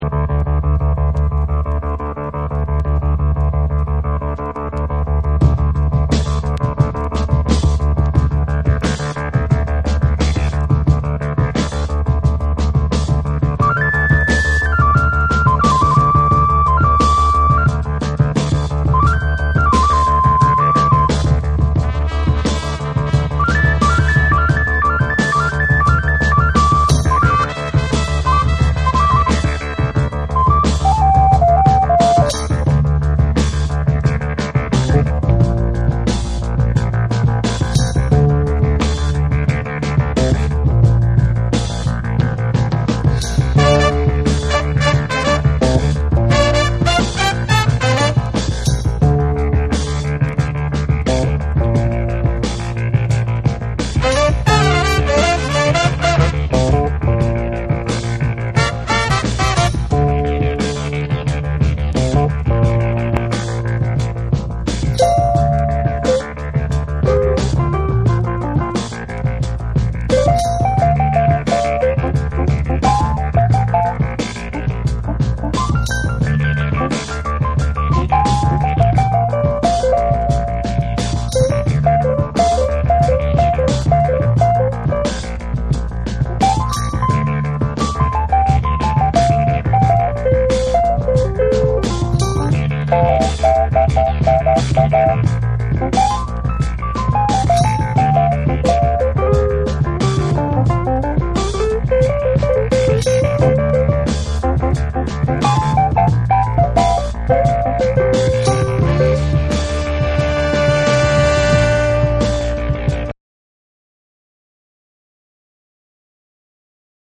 SOUL & FUNK & JAZZ & etc